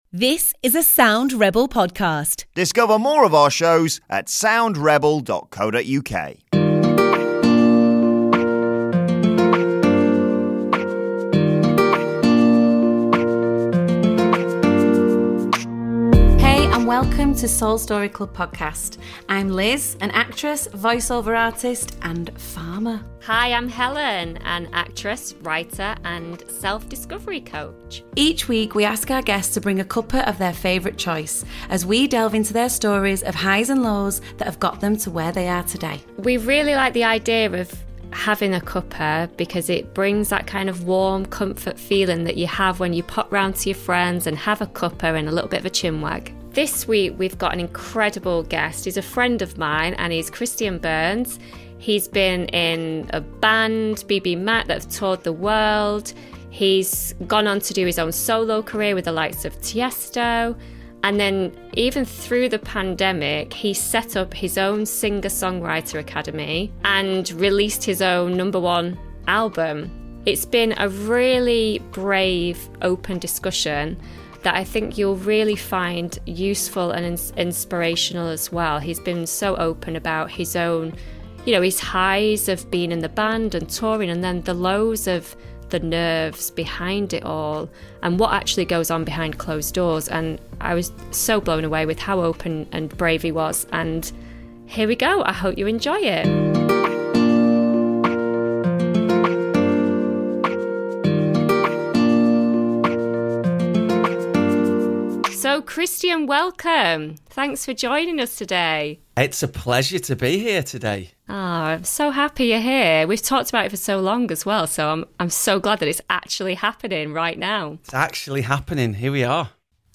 In this episode we’re joined by singer-songwriter Christian Burns who achieved worldwide fame with band BBMak, selling over 3 million albums, and who has since had huge success writing songs with EDM artists such as Tiesto, Armin van Buuren and BT.